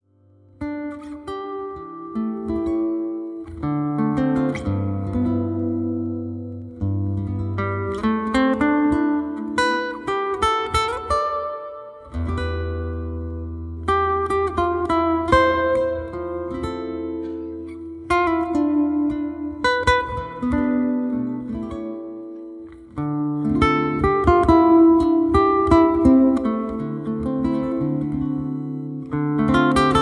Soothing and Relaxing Guitar Music